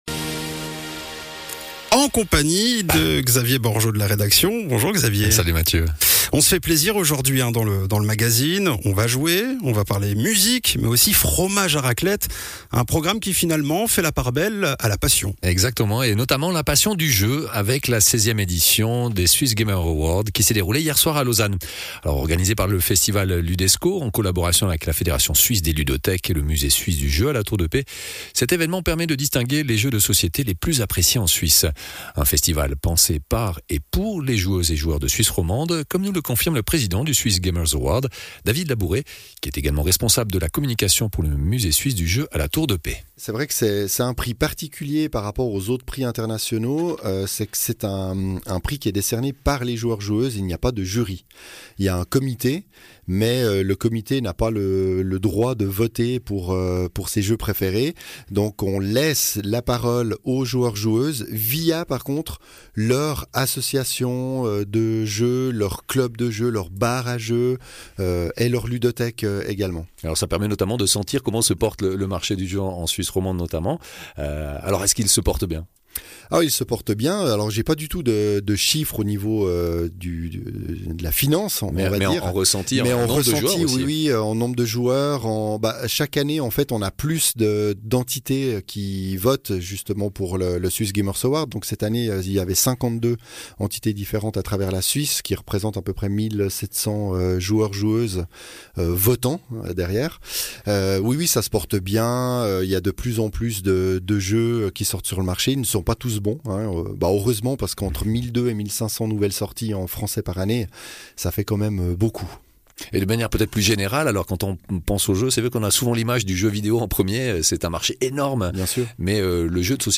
Intervenant(e)